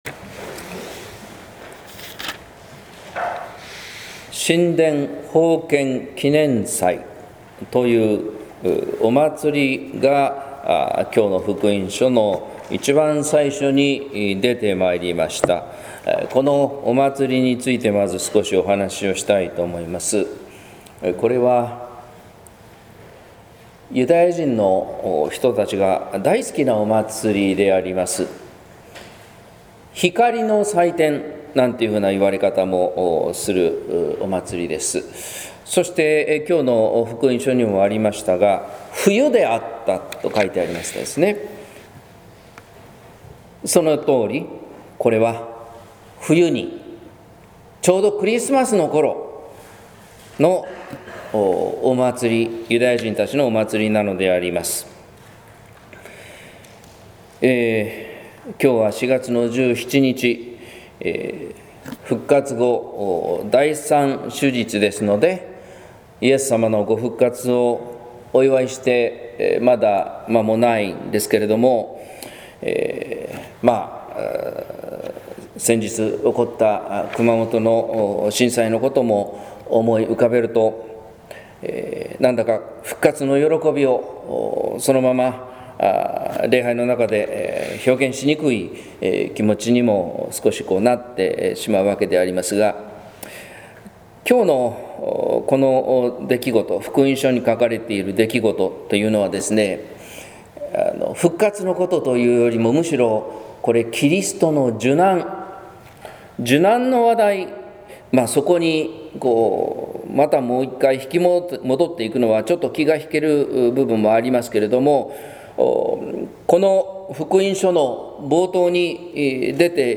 説教「羊の声を聞き分ける」（音声版）